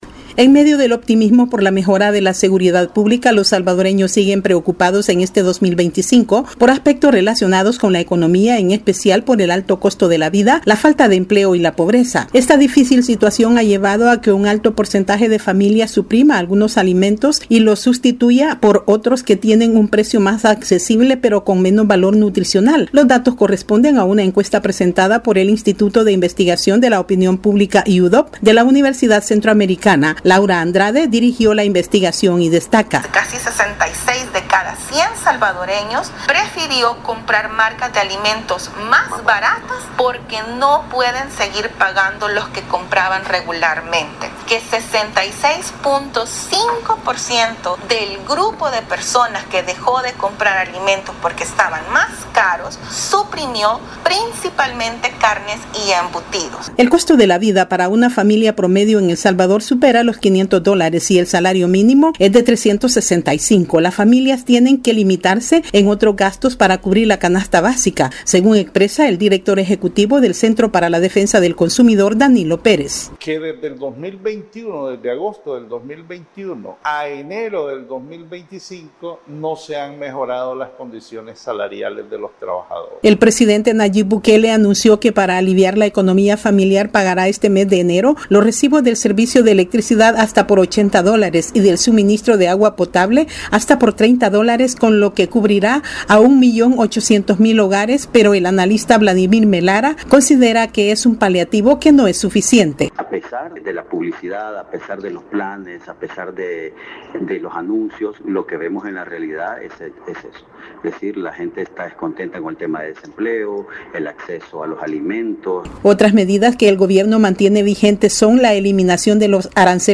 La difícil situación económica y los bajos salarios preocupan a los salvadoreños y los ha obligado a reducir los alimentos nutricionales debido a su alto costo. Desde El Salvador informa la corresponsal de la Voz de América